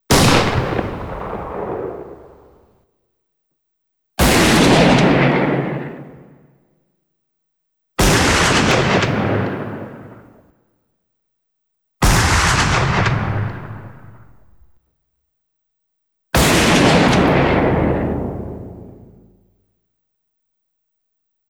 79-shotgun-gun-shots-x-5.m4a